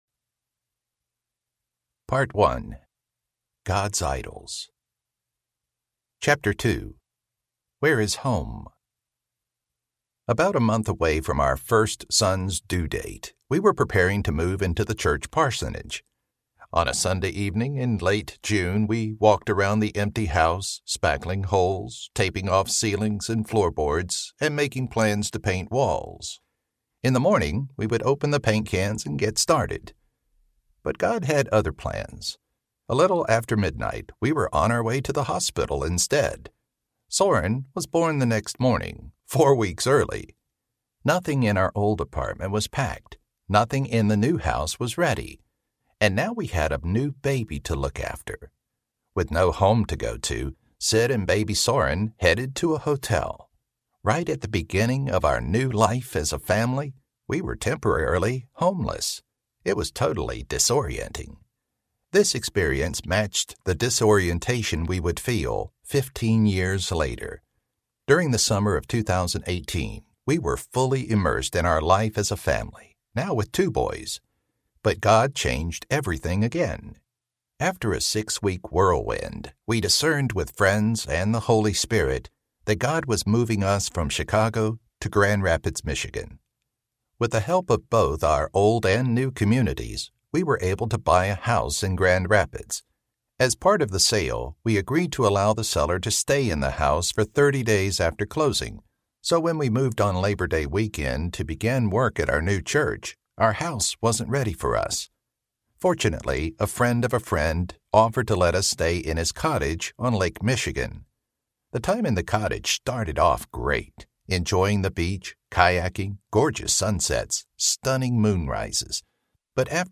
Does God Really Like Me? Audiobook
Narrator
7.0 Hrs. – Unabridged